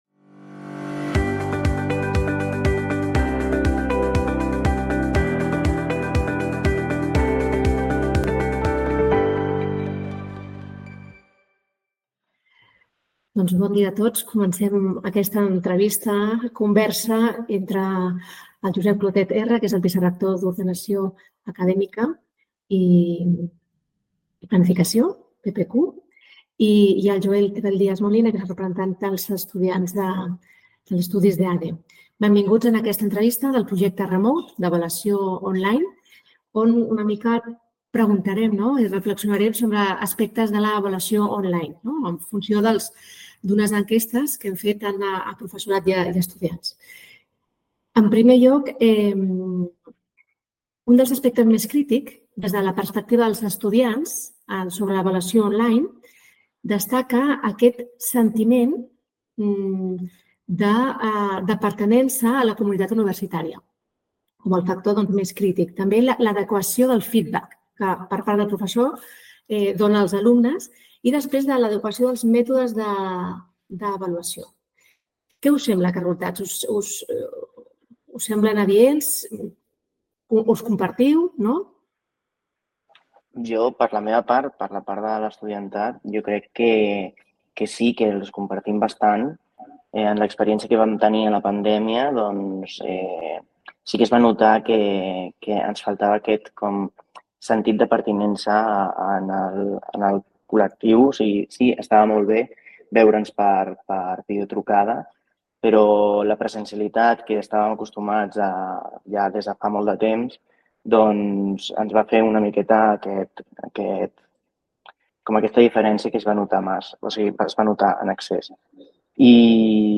In-Depth Interviews